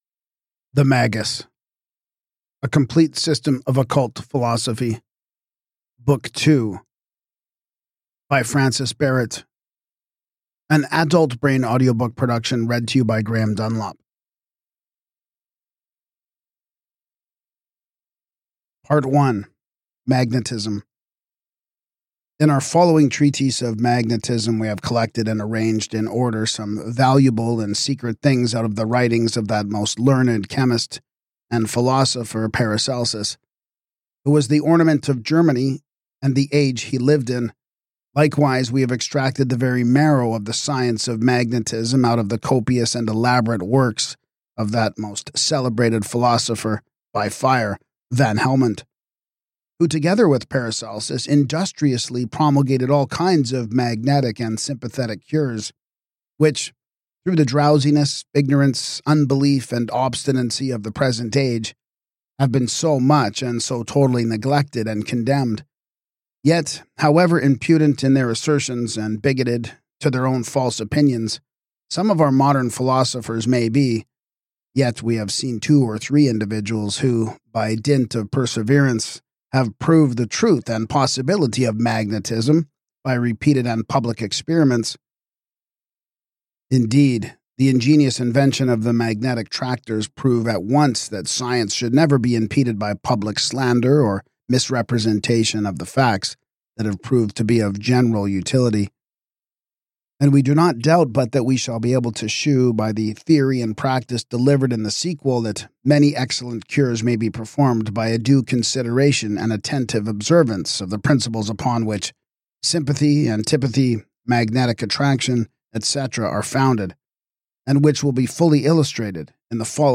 Whether you’re a student of Hermeticism, a practicing magician, or a seeker of hidden truth, this audiobook offers an immersive, enlightening experience voiced with clarity and reverence.